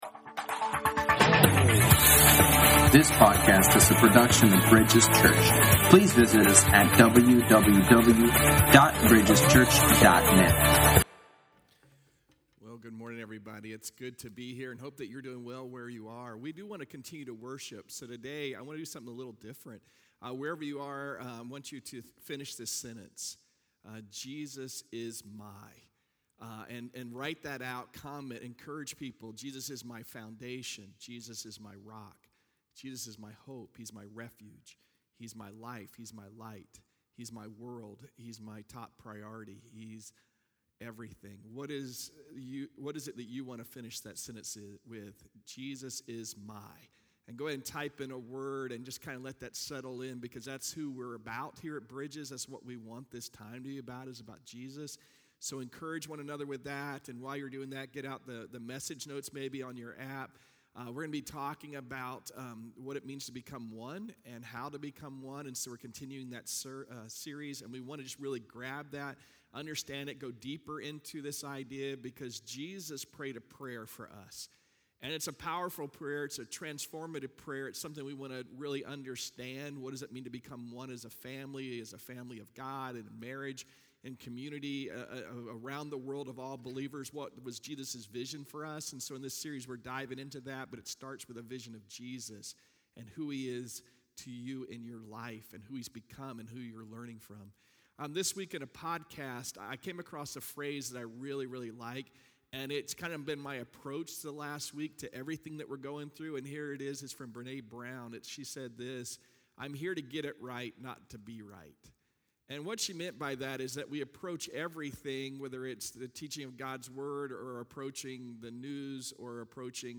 Sermons | Bridges Church